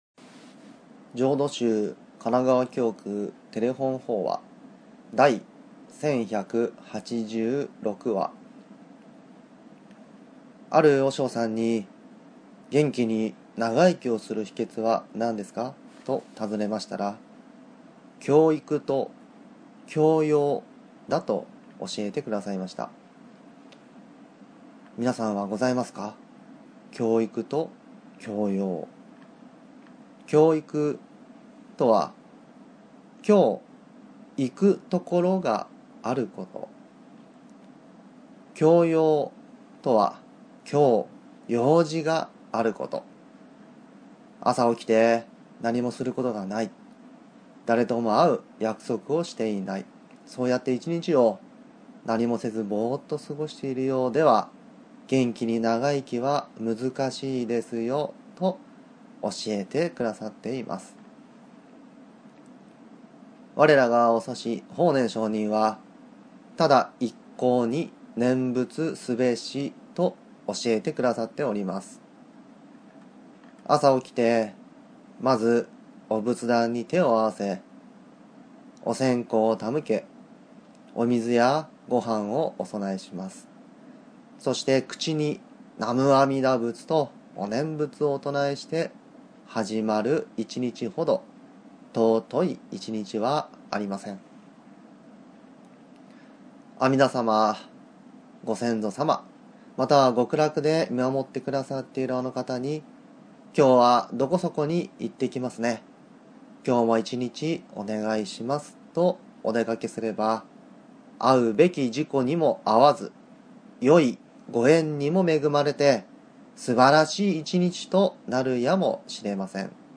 テレホン法話